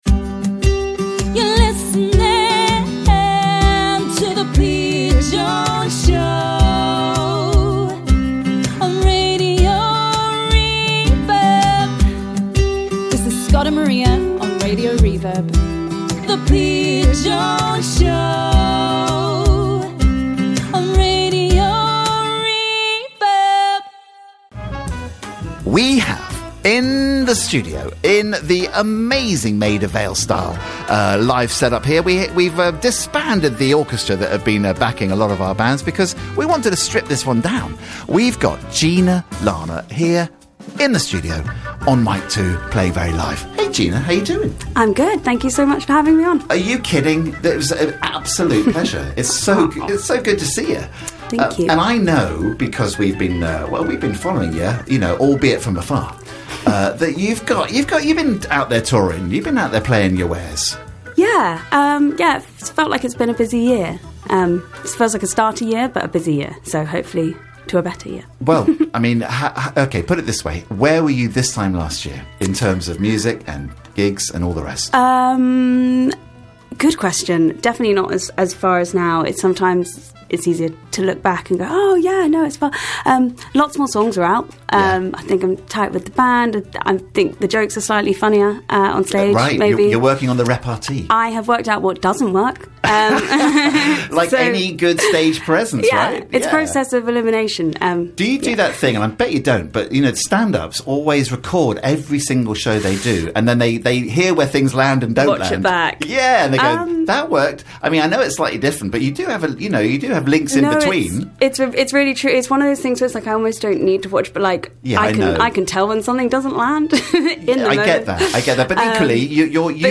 Three live tracks played: